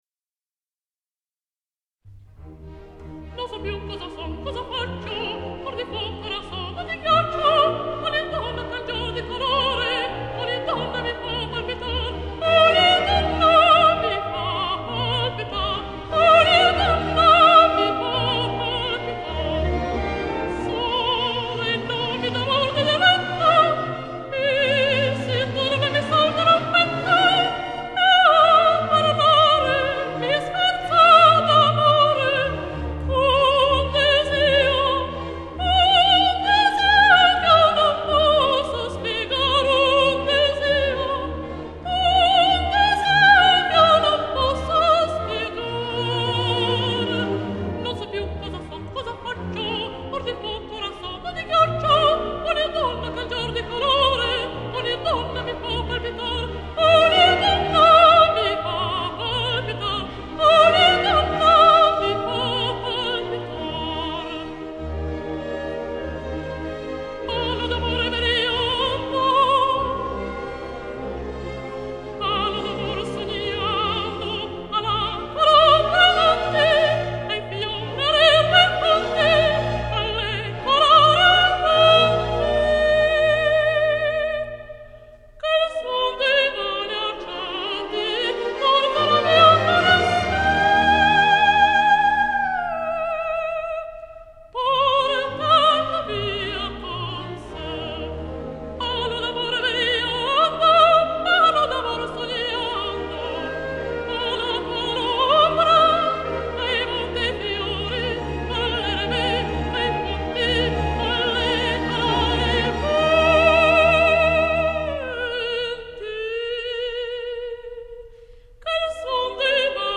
Несколько записей прекрасной румынской певицы меццо-сопрано Елены Черней ( 1924-2000)